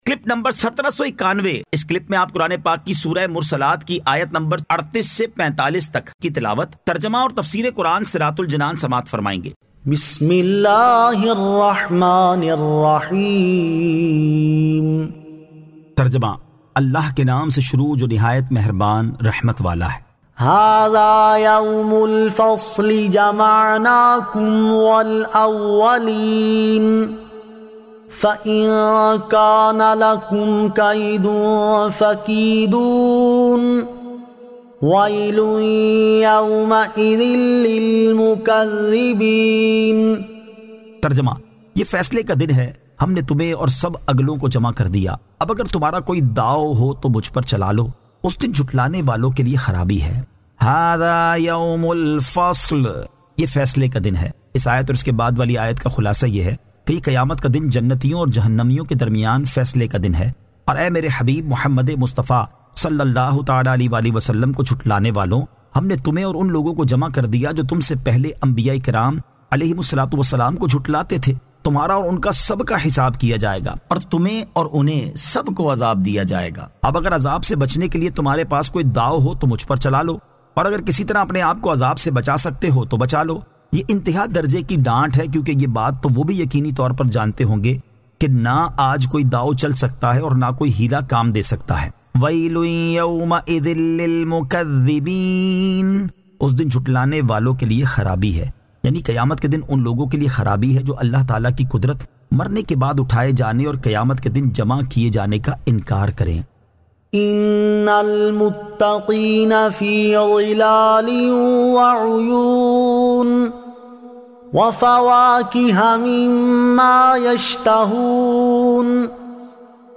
Surah Al-Mursalat 38 To 45 Tilawat , Tarjama , Tafseer